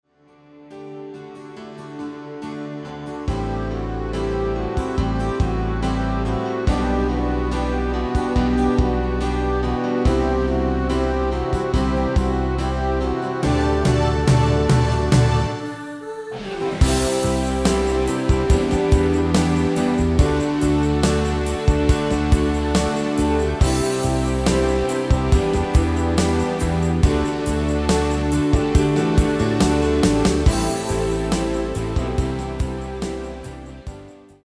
Demo/Koop midifile
- GM = General Midi level 1
- Géén vocal harmony tracks
Demo's zijn eigen opnames van onze digitale arrangementen.